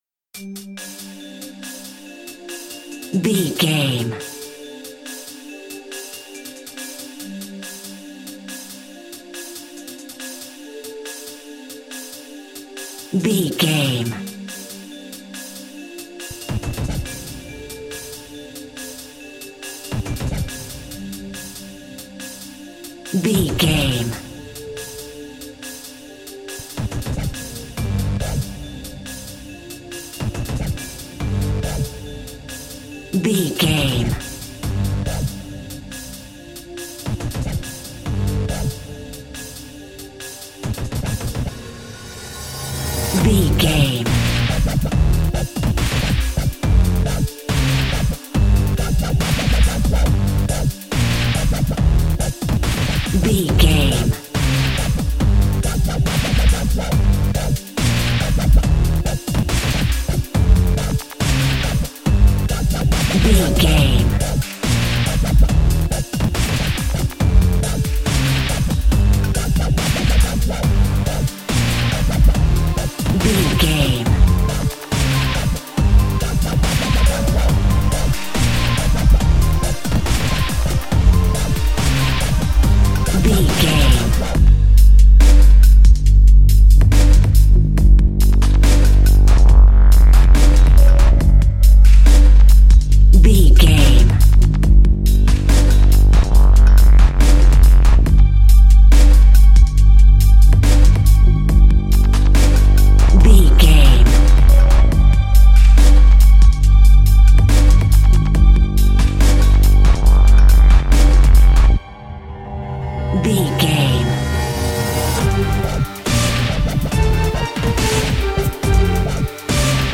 Aeolian/Minor
drum machine
synthesiser
orchestral
orchestral hybrid
dubstep
aggressive
energetic
intense
strings
drums
bass
synth effects
wobbles
epic